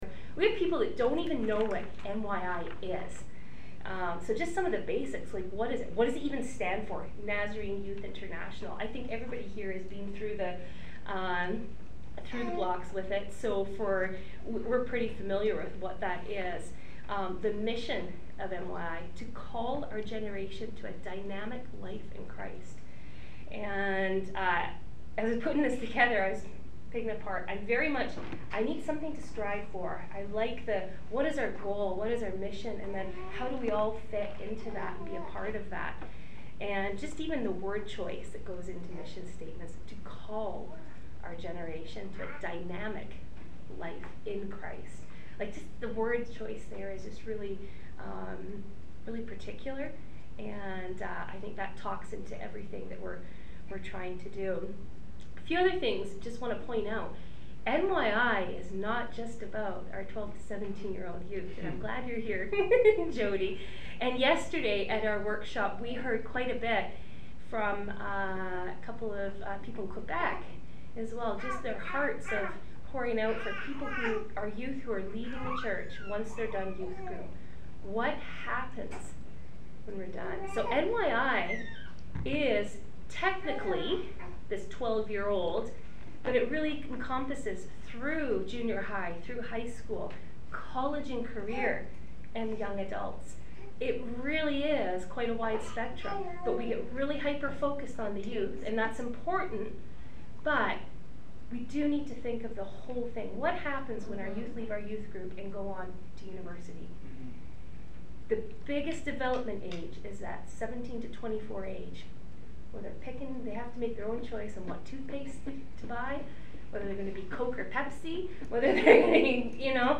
This workshop brings together Canadian youth leaders to hear updates from each other about events and strategies in youth ministry.